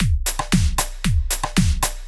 115 BPM Beat Loops Download